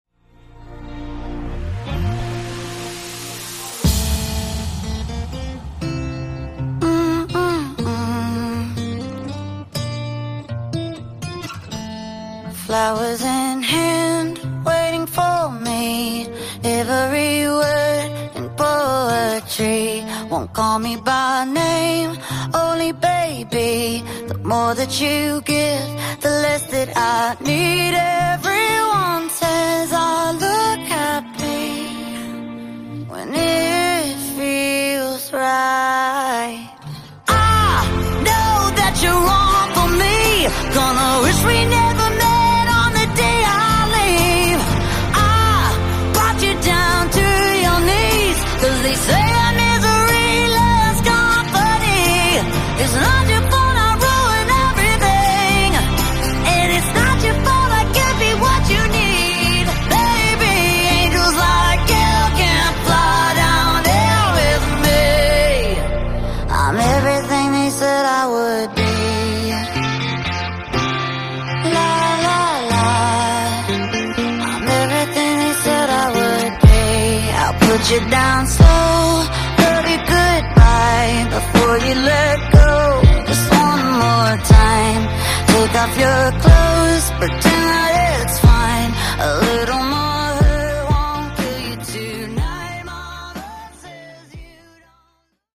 Genre: RE-DRUM Version: Clean BPM: 85 Time